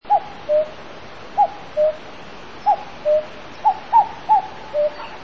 [カッコウ]
メトロノームのように、つい体を揺らしたくなる、その鳴き声。